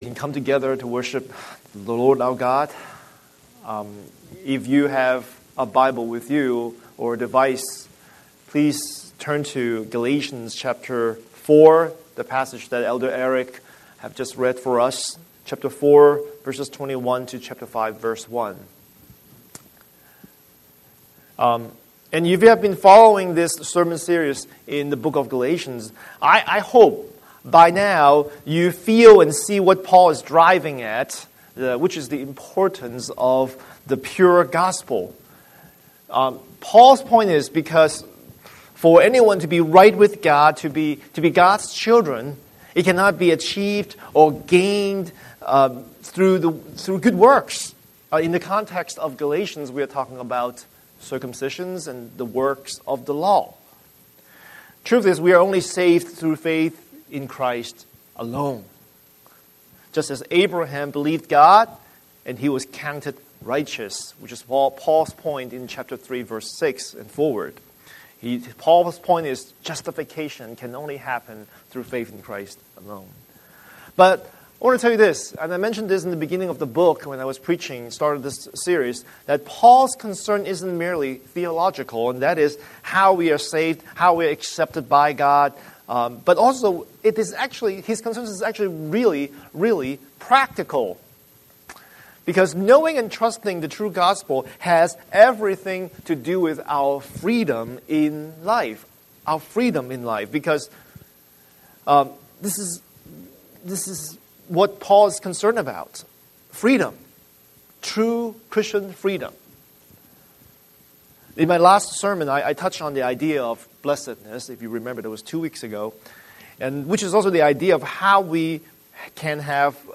Series: Sunday Sermon